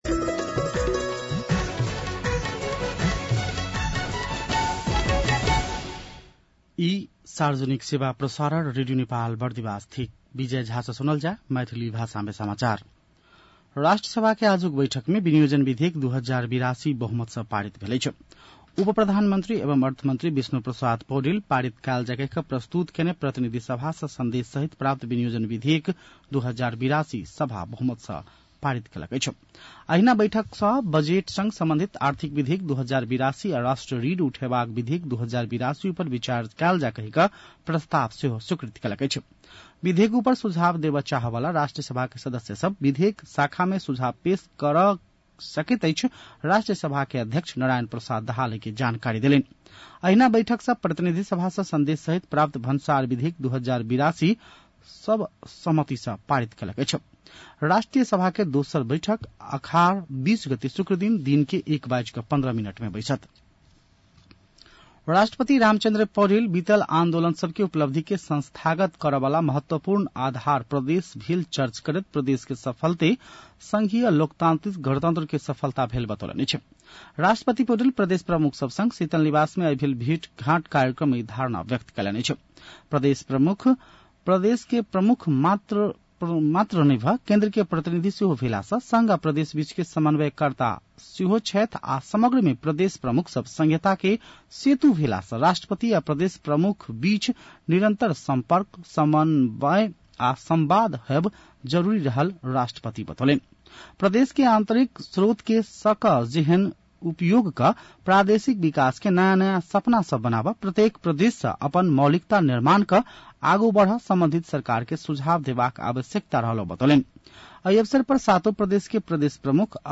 मैथिली भाषामा समाचार : १८ असार , २०८२